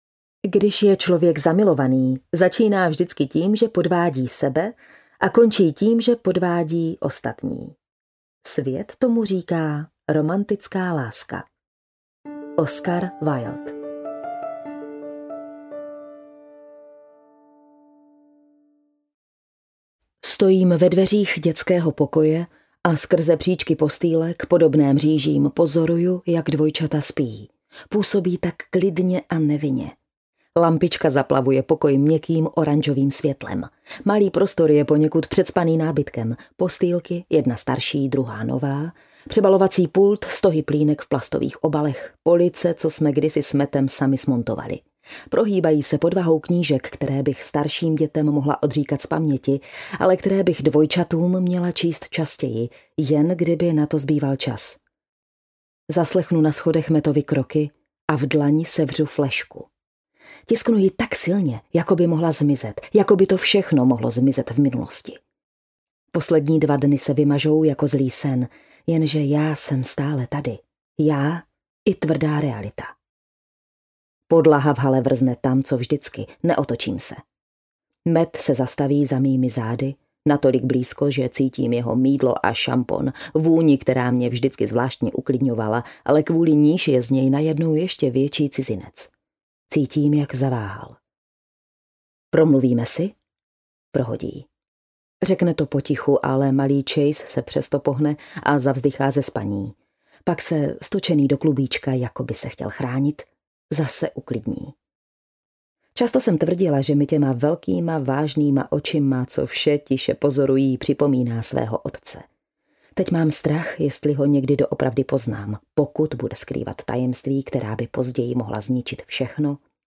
Musím to vědět audiokniha
Ukázka z knihy